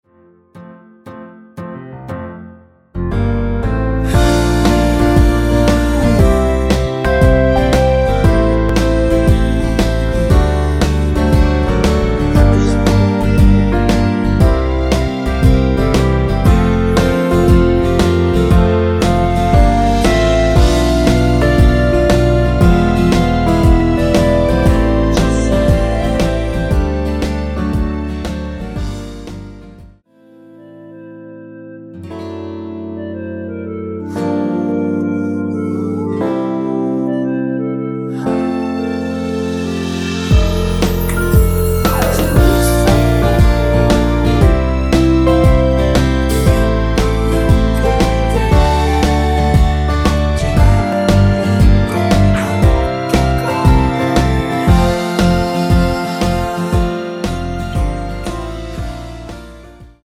전주 없이 시작하는 곡이라 전주 만들어 놓았습니다.(일반 MR 미리듣기 확인)
원키에서(-1)내린 멜로디와 코러스 포함된 MR입니다.(미리듣기 확인)
앞부분30초, 뒷부분30초씩 편집해서 올려 드리고 있습니다.